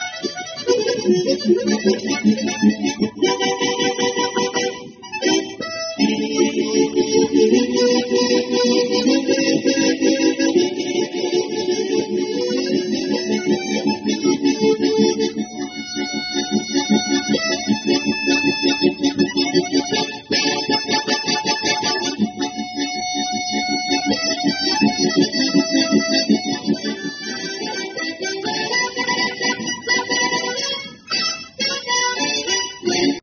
只见两位大爷在公园用口琴演奏
他们配合默契，气势磅礴
吹出了乐团的感觉！